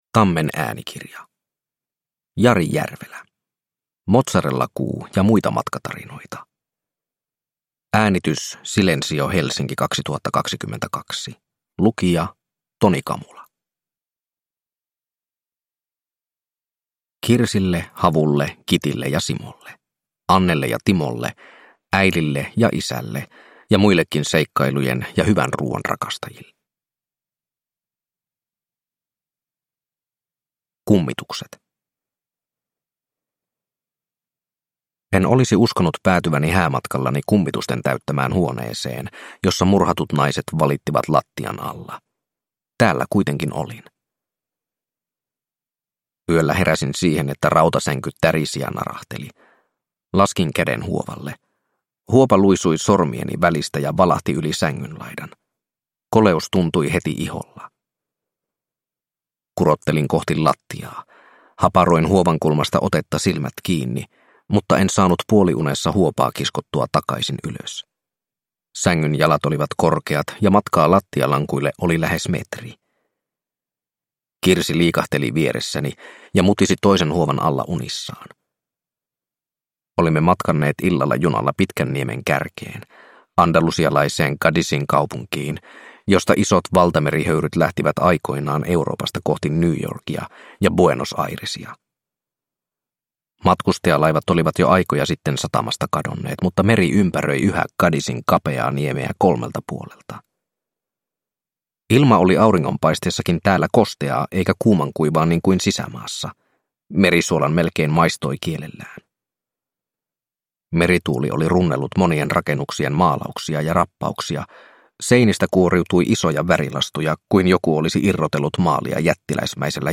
Mozzarellakuu ja muita matkatarinoita – Ljudbok